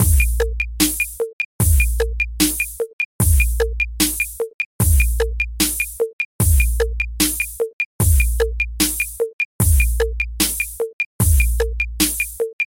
808 Dubstepish电子节拍
描述：电音dubstep听起来像808套件的节拍
Tag: 150 bpm Electronic Loops Drum Loops 2.15 MB wav Key : Unknown